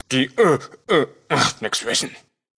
Kategorie:Fallout: Audiodialoge Du kannst diese Datei nicht überschreiben.